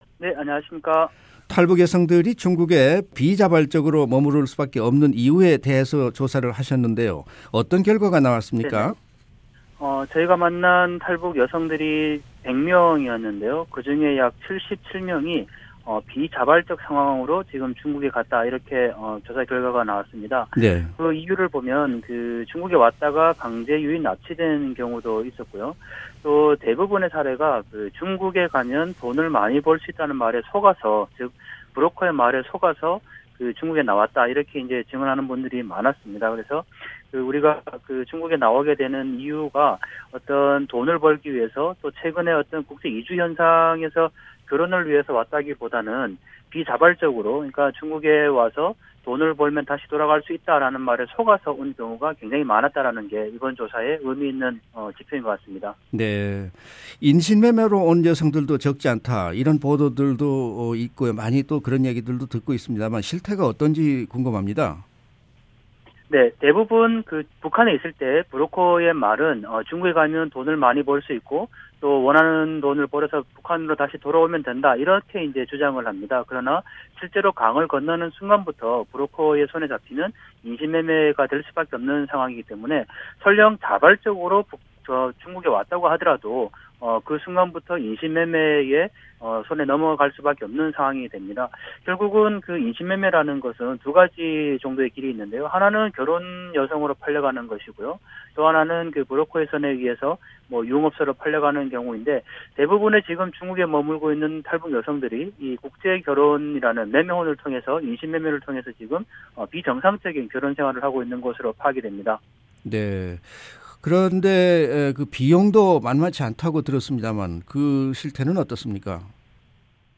[인터뷰 오디오